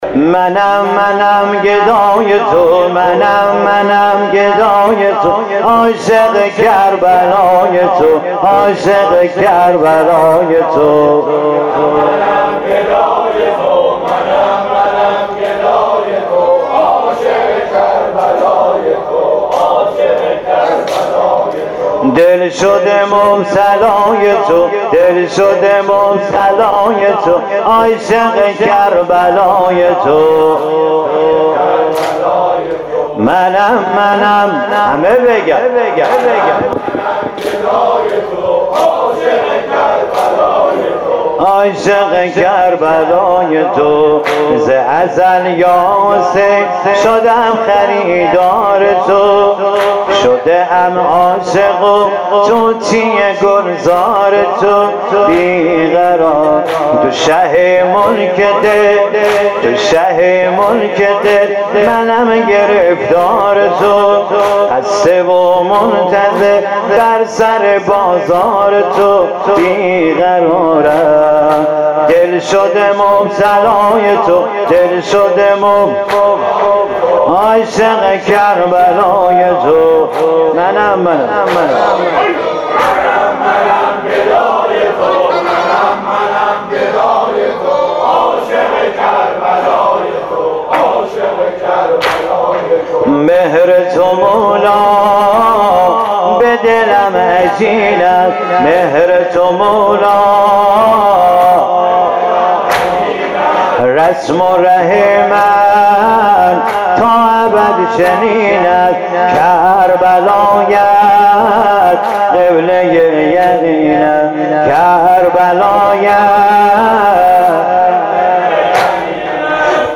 محرم و صفر 1396